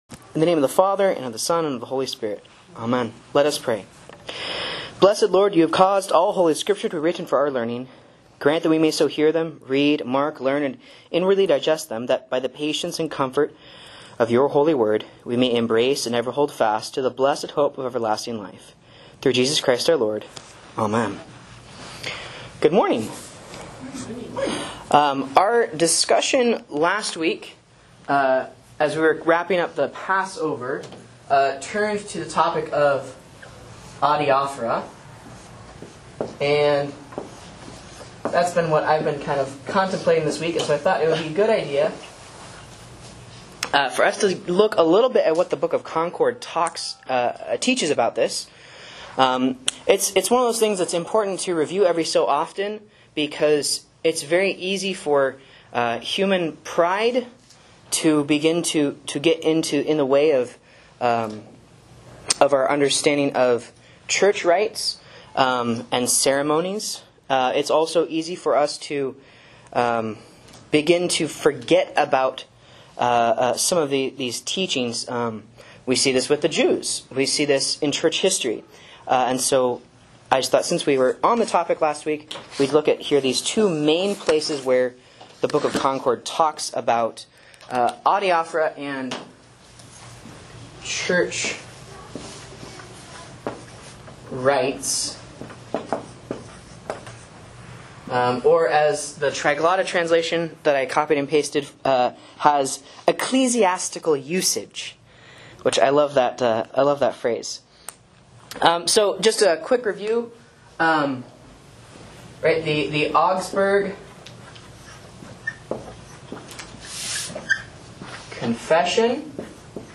Sermons and Lessons from Faith Lutheran Church, Rogue River, OR
A Class on Augsburg Confession Article 15